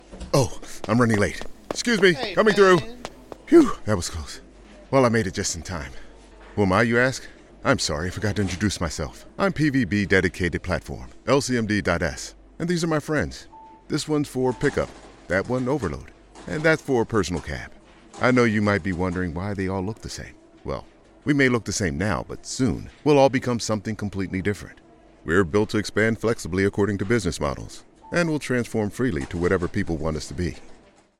Male voice actor
Articulate, Funny, Deep, Corporate